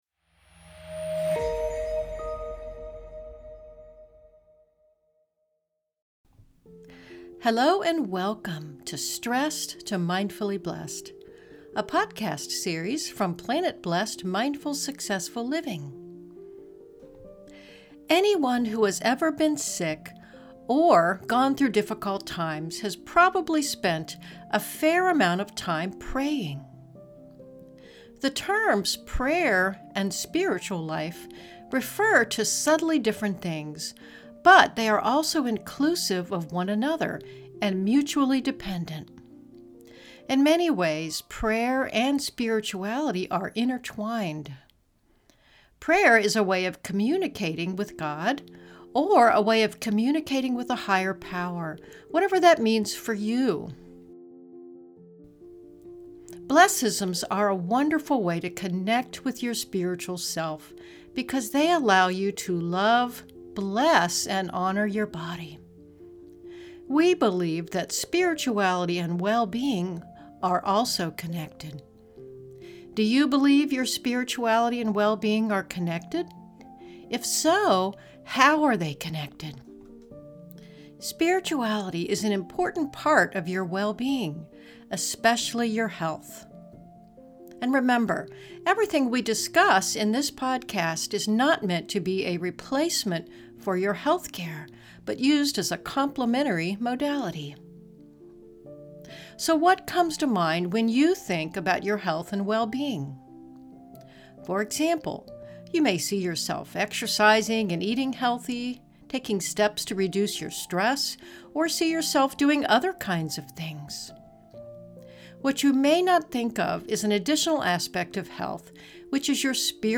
This episode is a brief introduction to Mindful BLESSisms for Awakening Your Innate Intelligence and Self-Healing Ability. The short meditation in this introductory session is designed to help you connect with your innate intelligence and your SMART Body via the SMART Body Pledge.